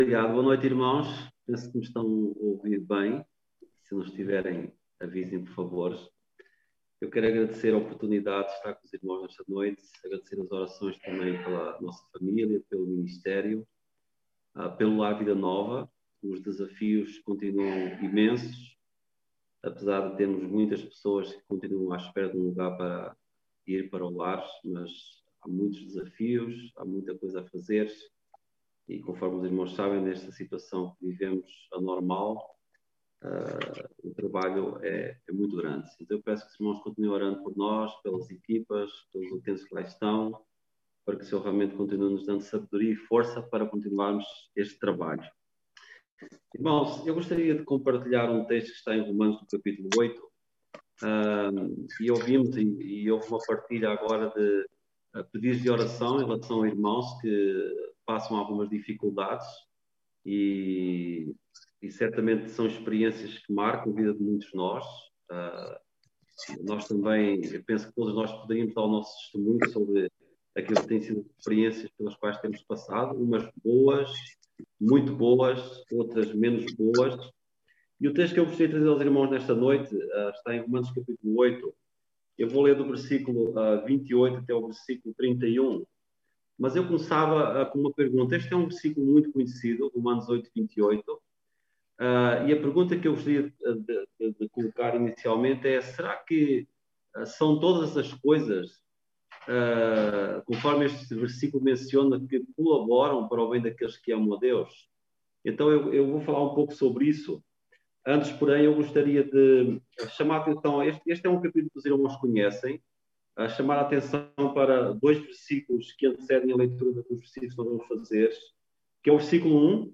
Pregações